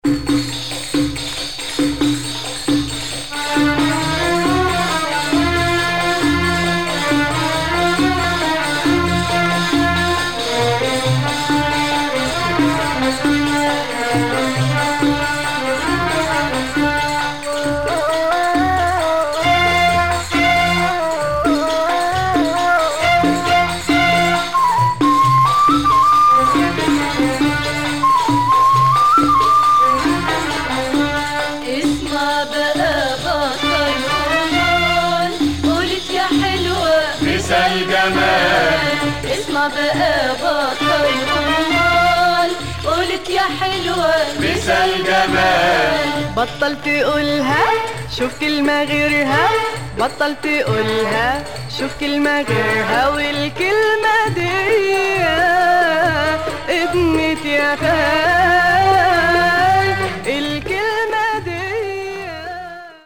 Blond Lebanese female singer
Arabic & Persian